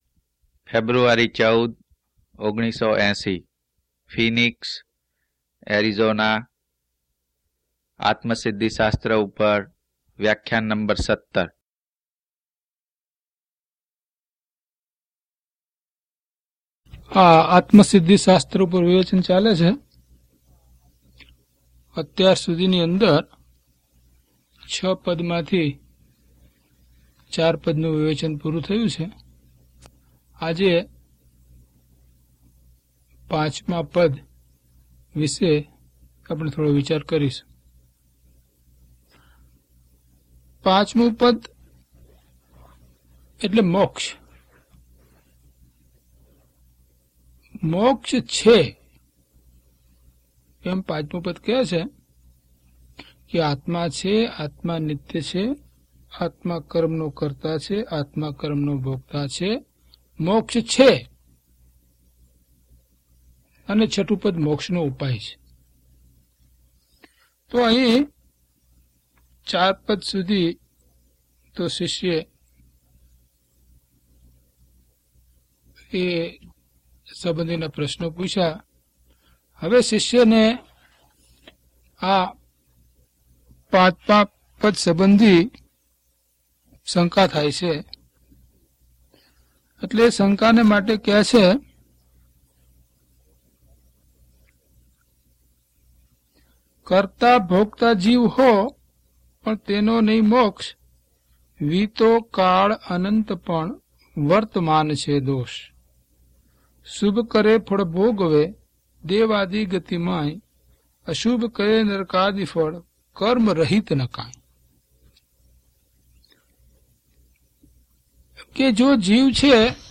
DHP026 Atmasiddhi Vivechan 17 - Pravachan.mp3